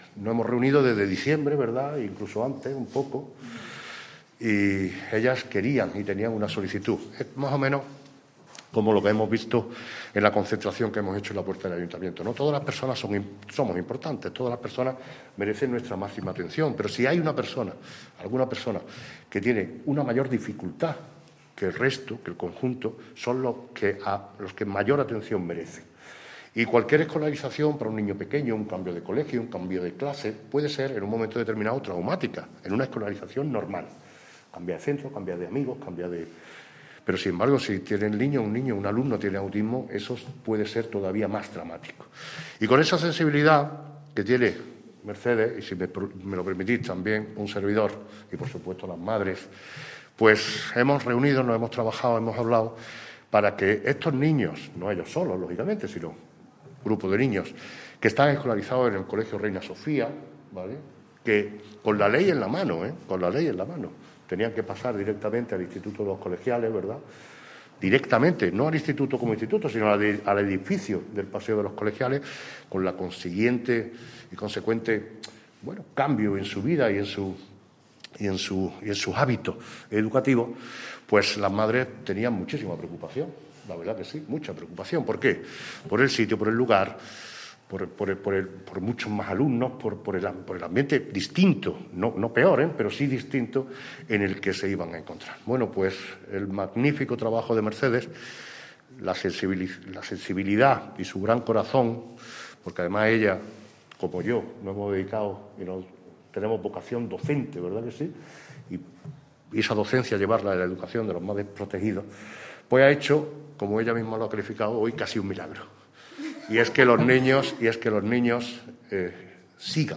El alcalde de Antequera, Manolo Barón, y la delegada territorial de la Consejería de Educación en Málaga, Mercedes García Paine, han comparecido en el mediodía de hoy ante los medios de comunicación para informar de positivas novedades respecto a las peticiones de las familias de unos 17 alumnos con necesidades educativas especiales actualmente matriculados en el CEIP Reina Sofía que, gracias a las gestiones realizadas, podrán continuar su formación específica hasta los 21 años en el propio Reina Sofía.
Cortes de voz